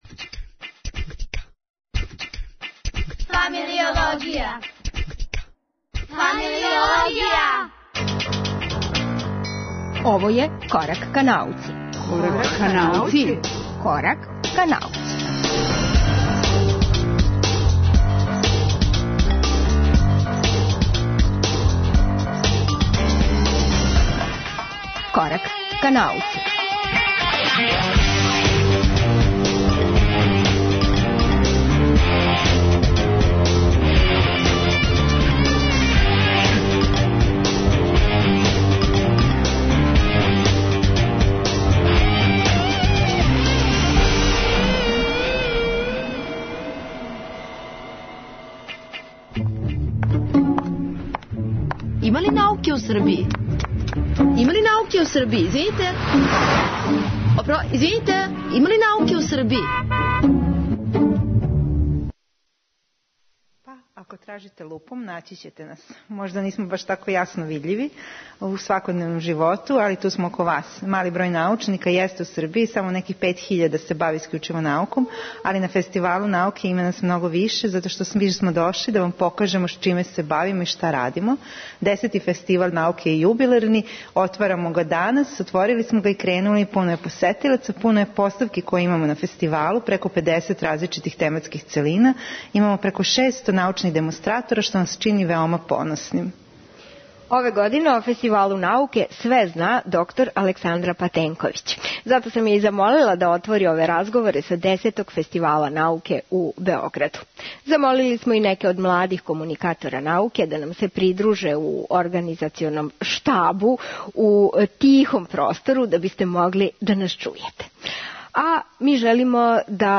Овај Корак ка науци реализујемо са Десетог фестивала науке. Обићи ћемо нове поставке, упознати попурализаторе науке и дружити се са експерт-тинејџерима.